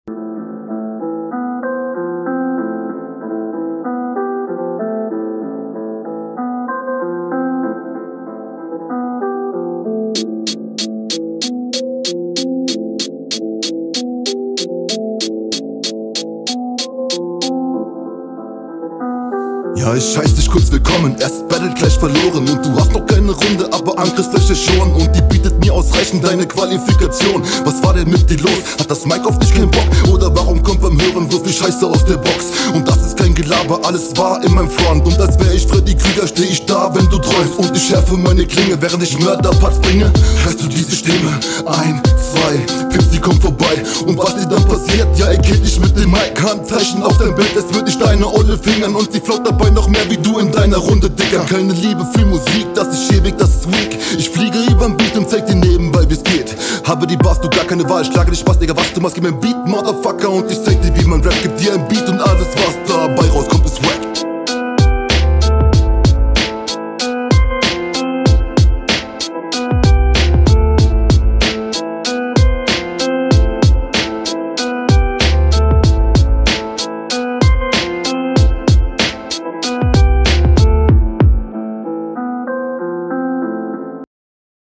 All in all ein grundsolider Flow, der in Silber perfekt eingeordnet ist.